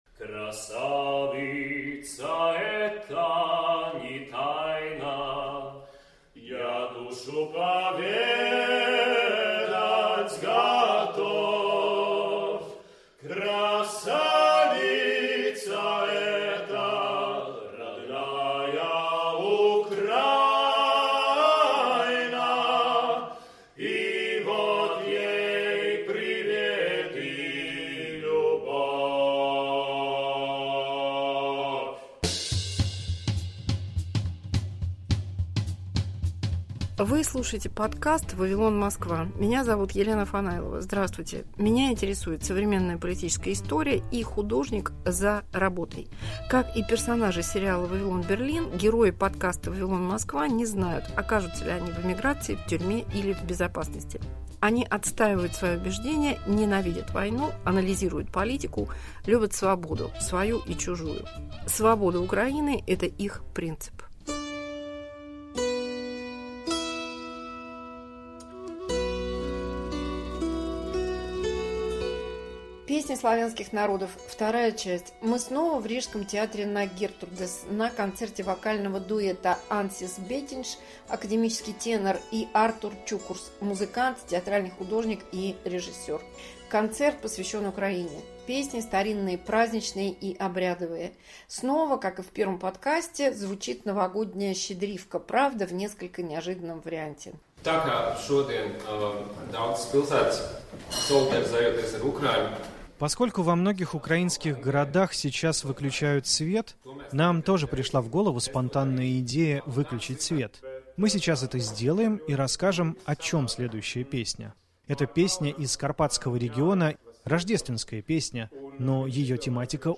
Вторая часть рождественского концерта в Риге.
Рождественский концерт в Риге.